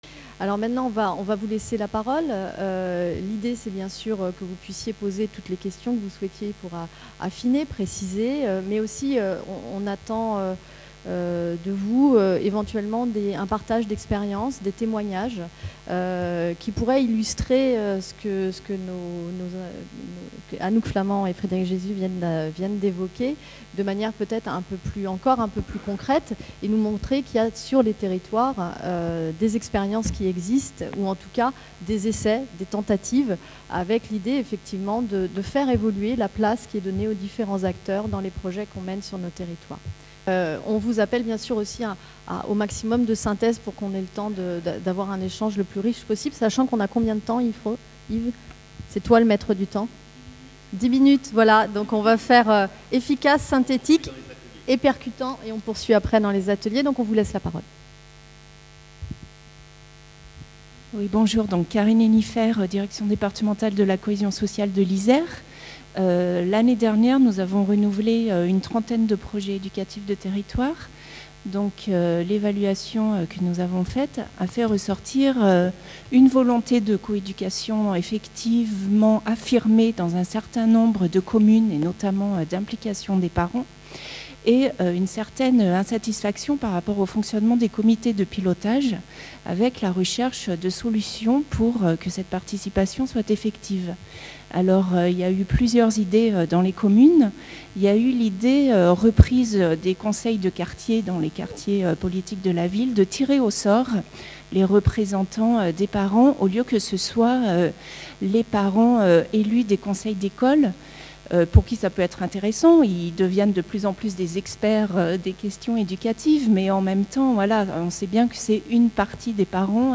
Conférence.